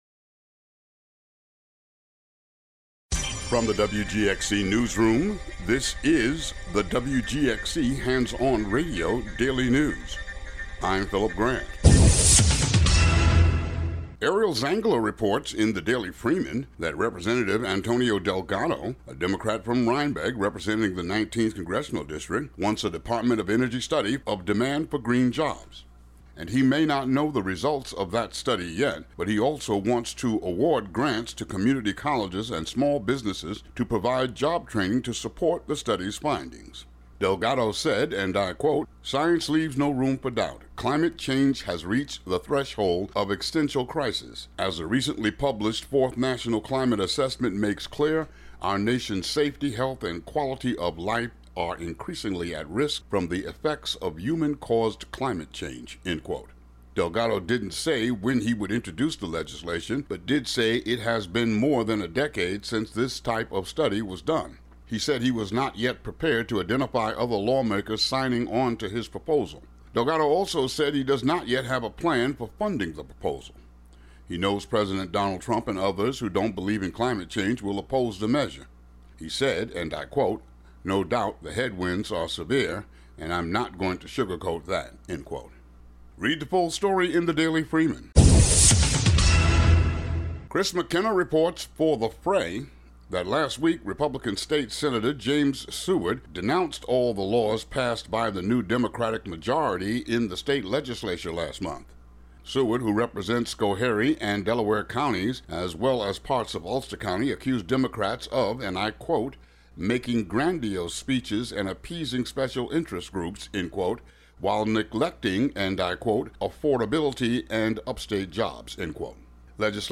Uncategorized Local headlines and weather